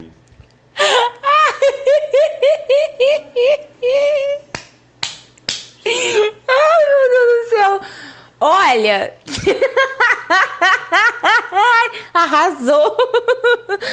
Risada Arrasou